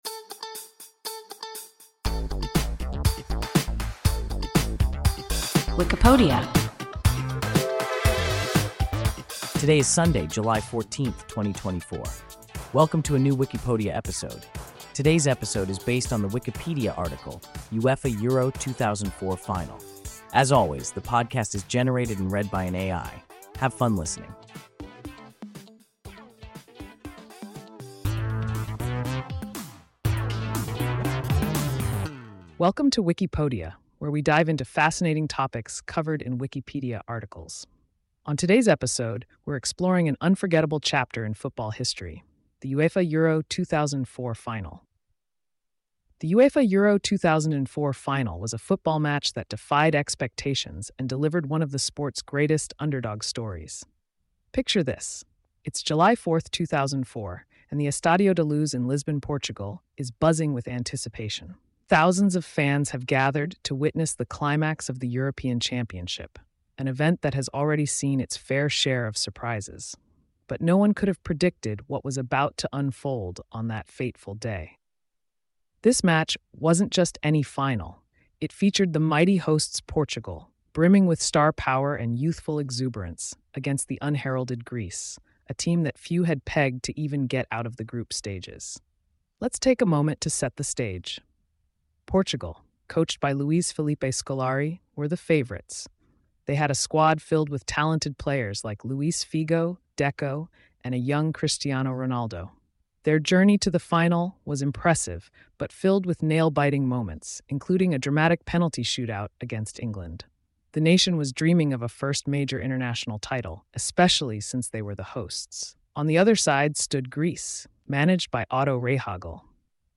UEFA Euro 2004 final – WIKIPODIA – ein KI Podcast